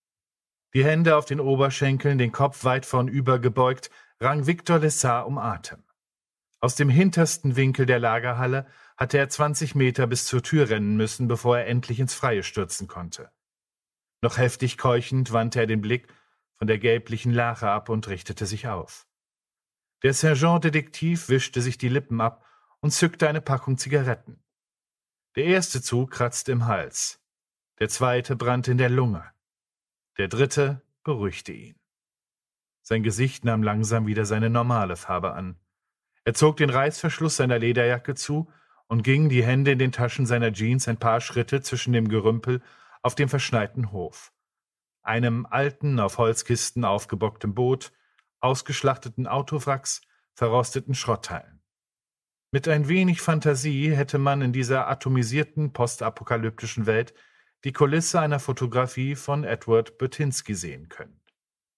2020 | Ungekürzte Lesung